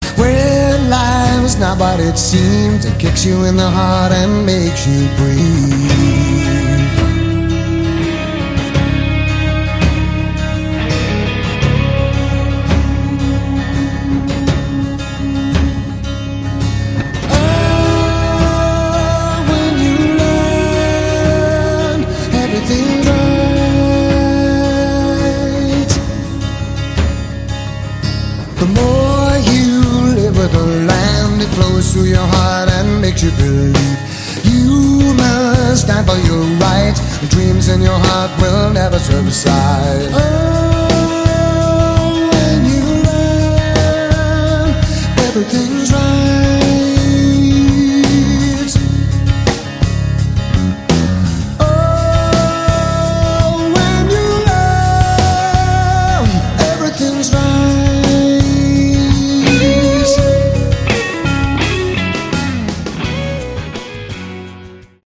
Category: AOR
Drums
Vocals
Bass
Keyboards, Guitar
Lead Guitar